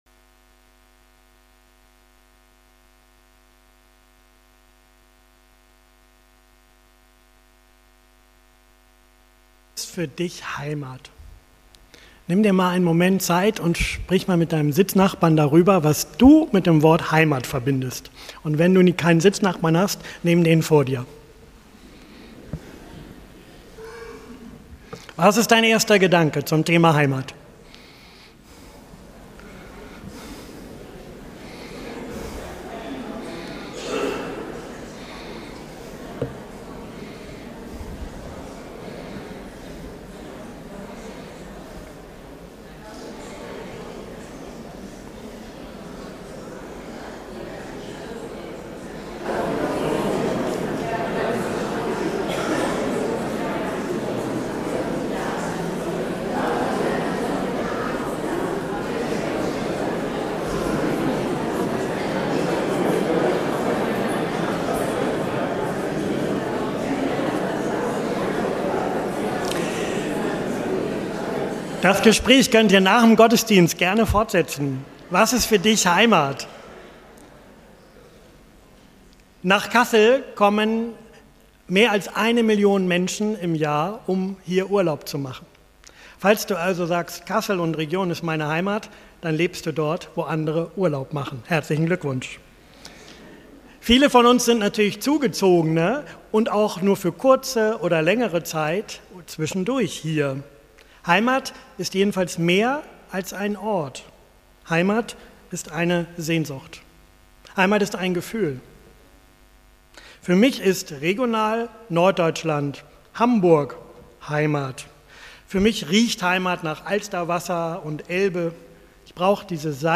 predigt über die Sehnsucht nach Heimat und Klagelieder 3, 19-24